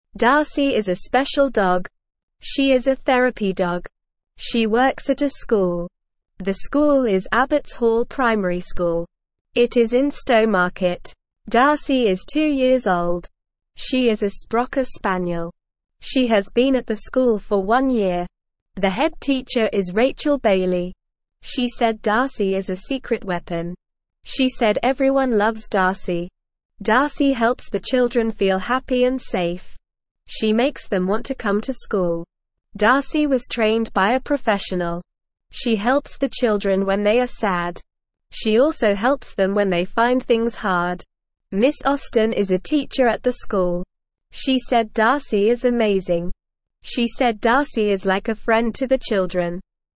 Normal
English-Newsroom-Beginner-NORMAL-Reading-Special-Therapy-Dog-Helps-School-Kids.mp3